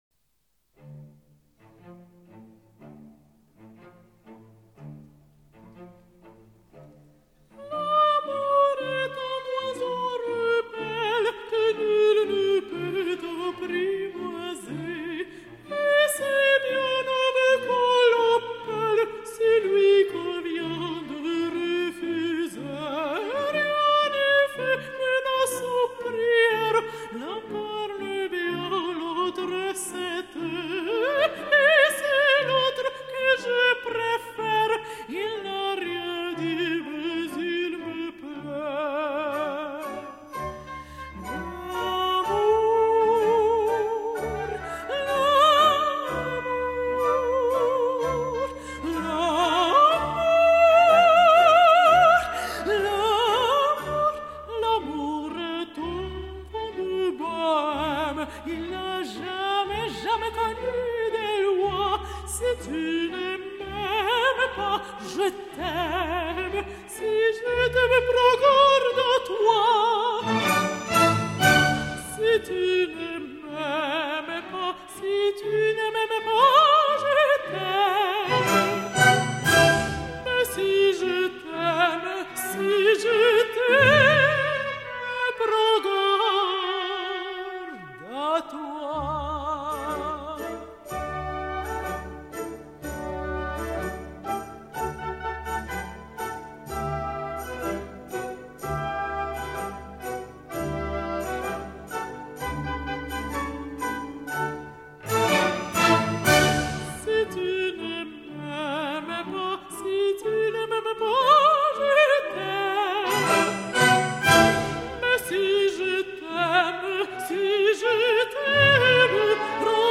ARIAS and DUETS